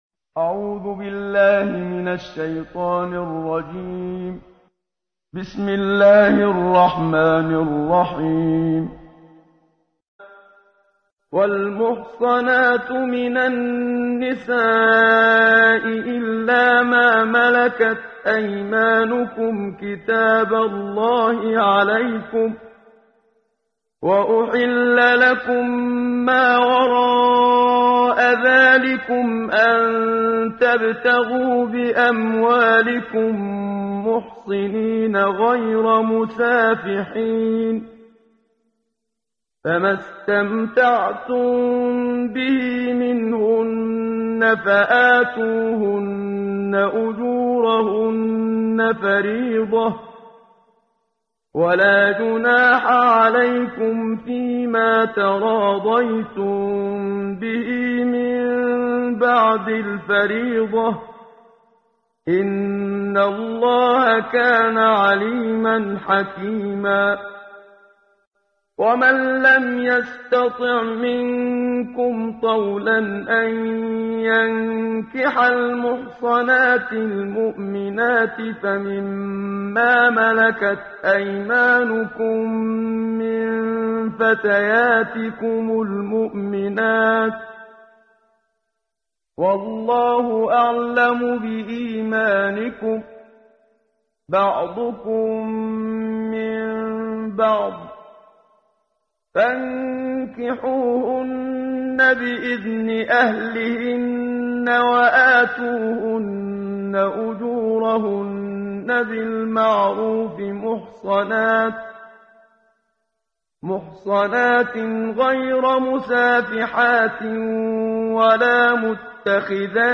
قرائت قرآن کریم ، صفحه 82، سوره مبارکه نساء آیه 20 تا 23 با صدای استاد صدیق منشاوی.